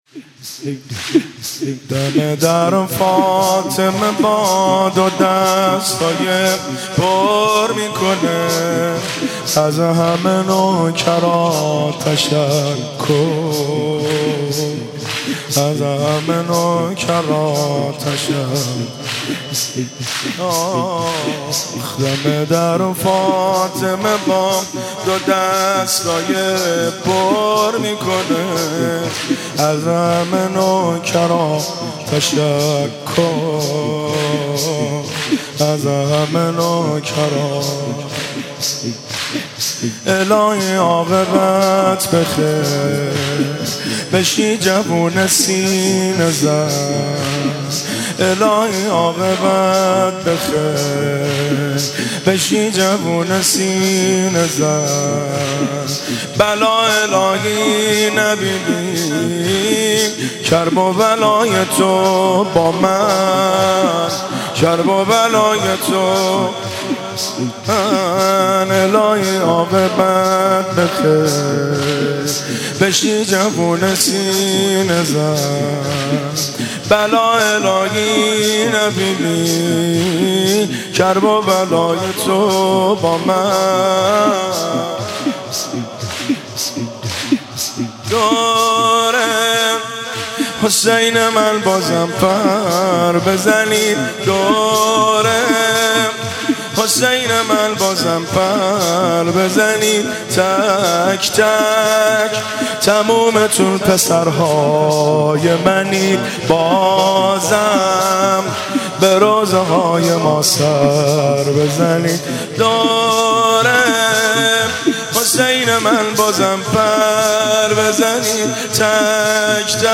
مناسبت : شهادت امام رضا علیه‌السلام
قالب : زمینه